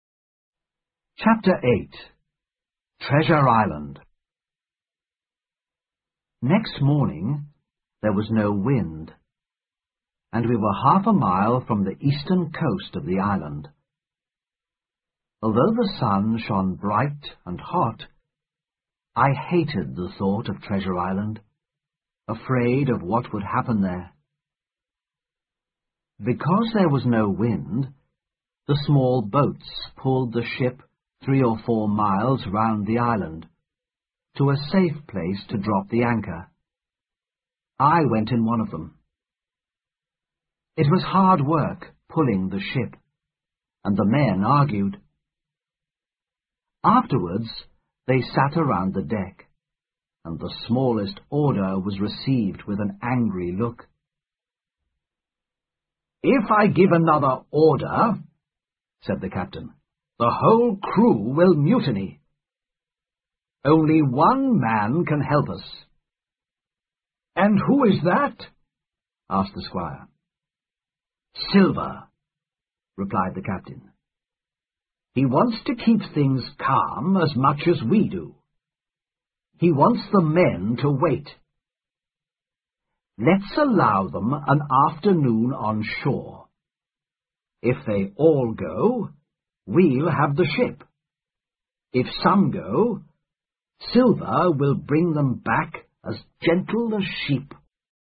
在线英语听力室《金银岛》第八章 金银岛(1)的听力文件下载,《金银岛》中英双语有声读物附MP3下载